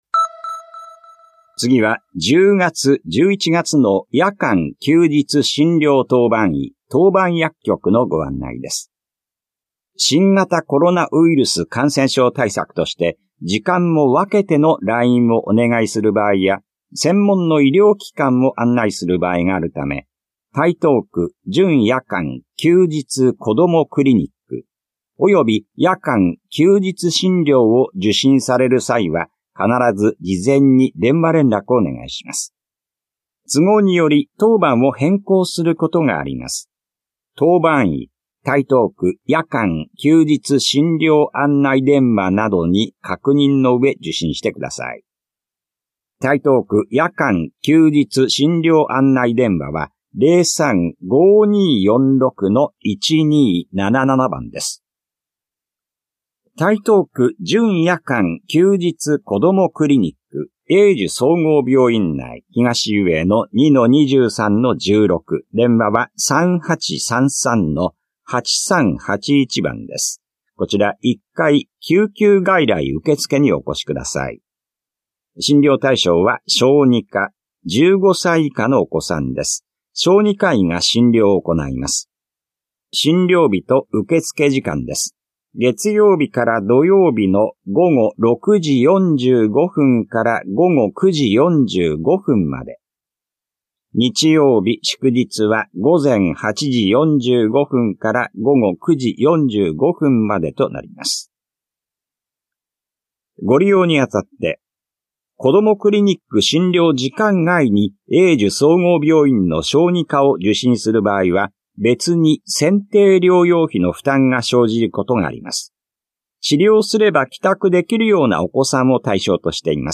広報「たいとう」令和6年10月5日号の音声読み上げデータです。